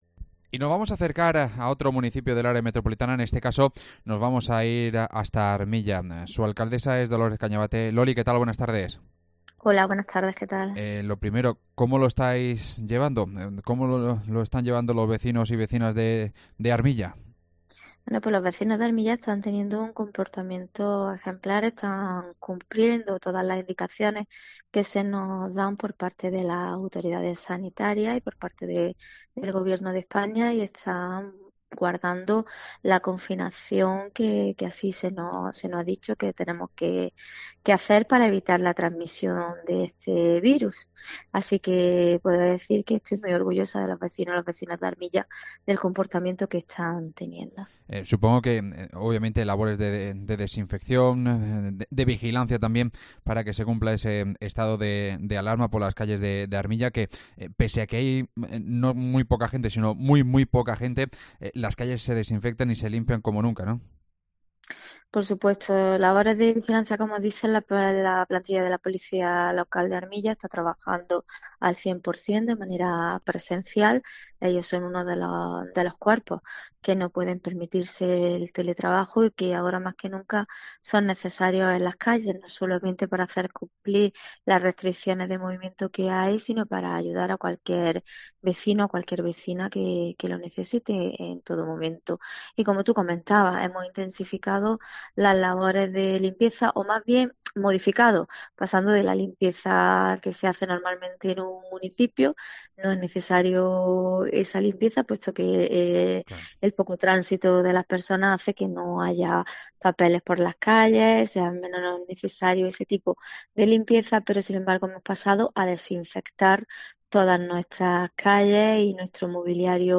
Entrevista con la alcaldesa de Armilla, Dolores Cañavate